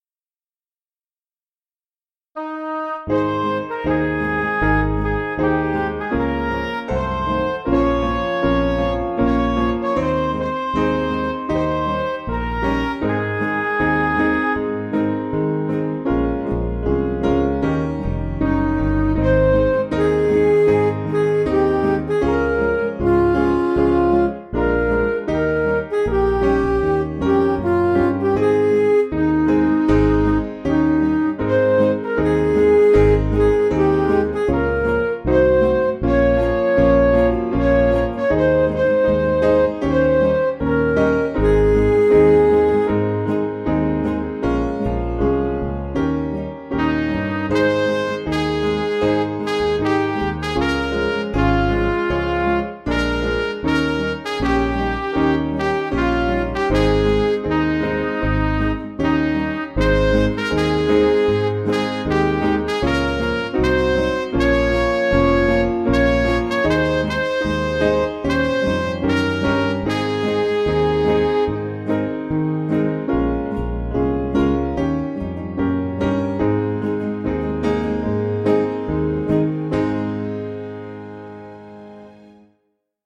Piano & Instrumental
(slower)